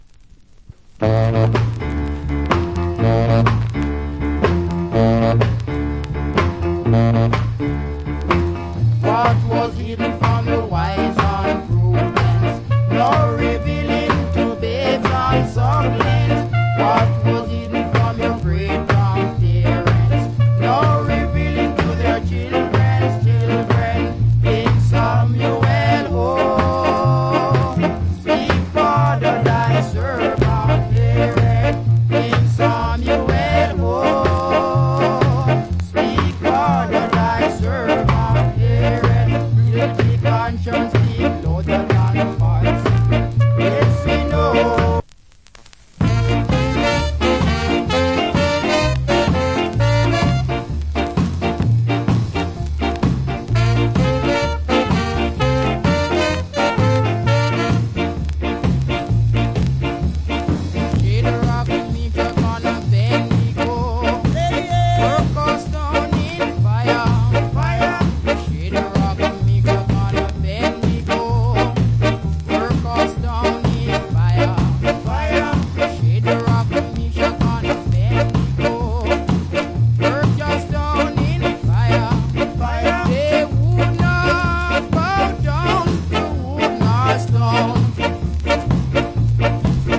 Great Ska Vocal.